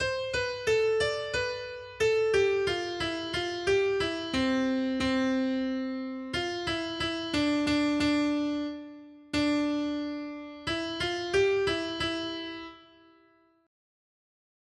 Noty Štítky, zpěvníky ol199.pdf responsoriální žalm Žaltář (Olejník) 199 Ž 41, 2-5 Ž 41, 13-14 Skrýt akordy R: Uzdrav mě, Pane, zhřešil jsem proti tobě. 1.